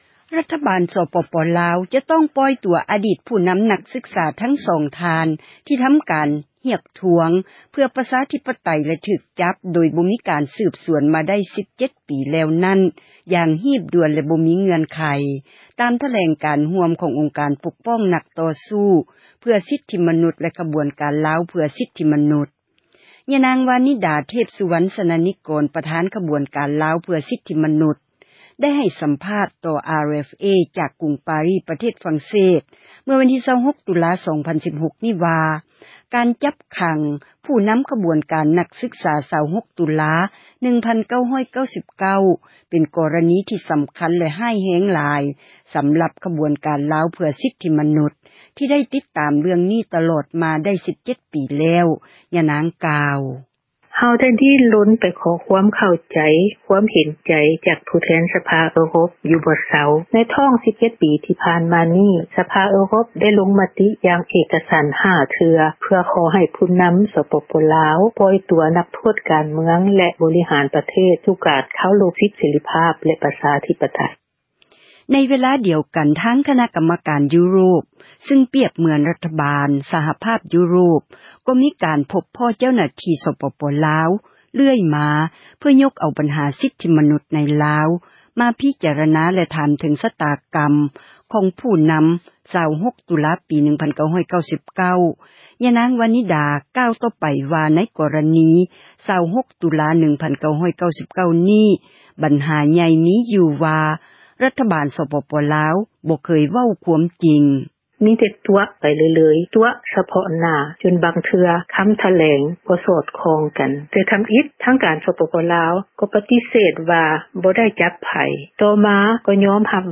ໃຫ້ສັມພາດ ຕໍ່ RFA ຈາກ ກຸງປາຣີ ປະເທດ ຝຣັ່ງເສດ